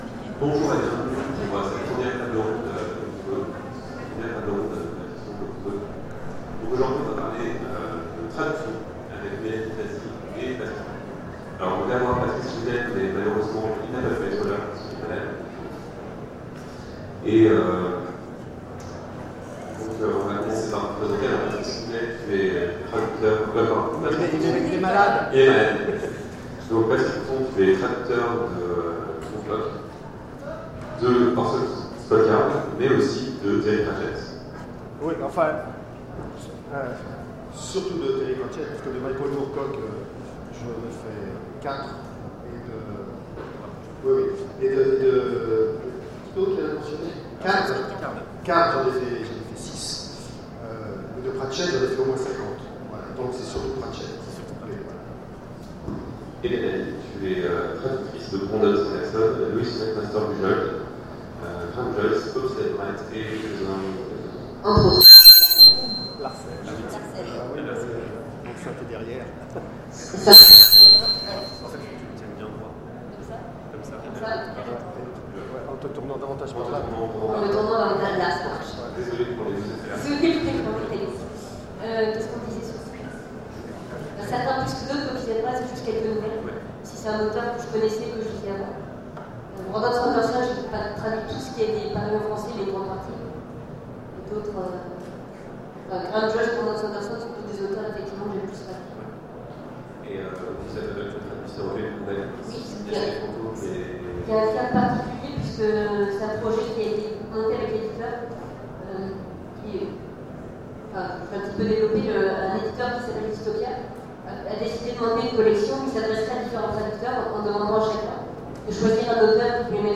Conférences Octogones 2015 : Ecrire pour l'écrivain ?